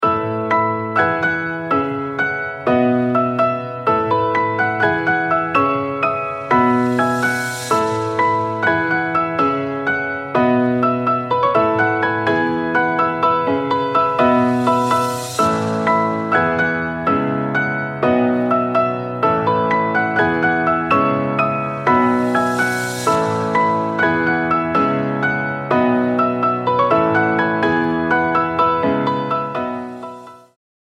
Catchy